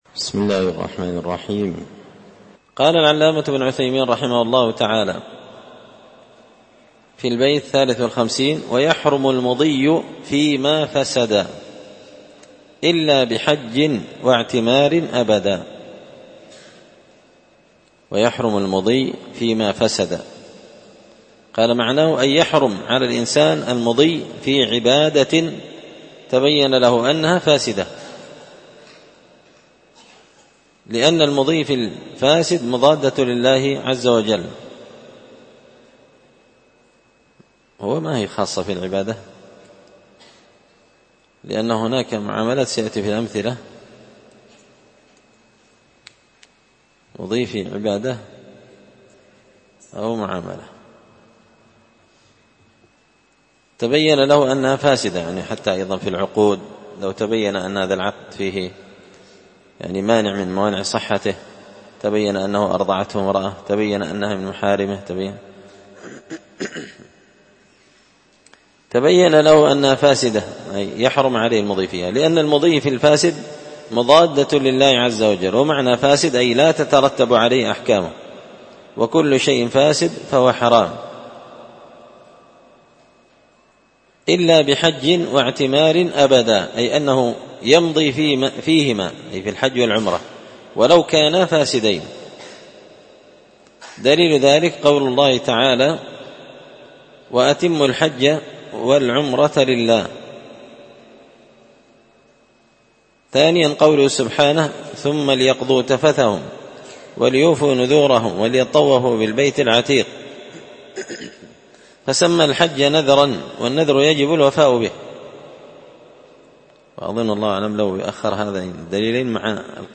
تسهيل الوصول إلى فهم منظومة القواعد والأصول ـ الدرس 30
مسجد الفرقان